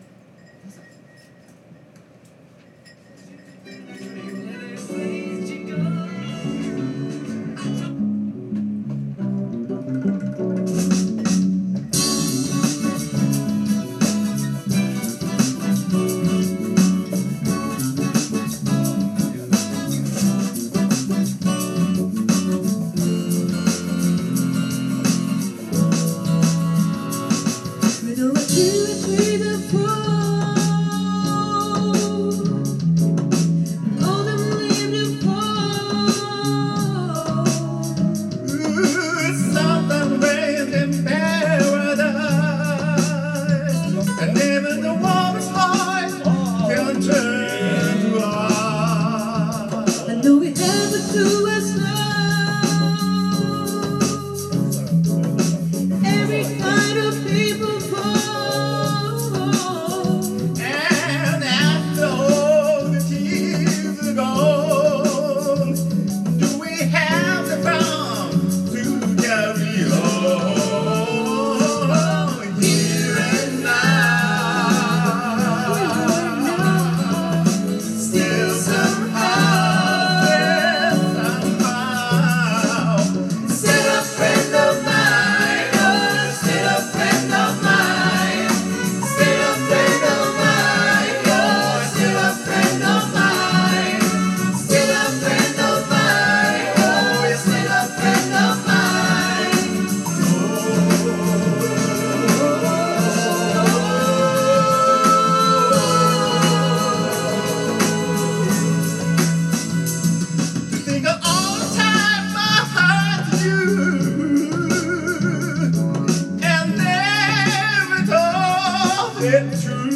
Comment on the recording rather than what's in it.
Duet & Chorus Night Vol. 13 TURN TABLE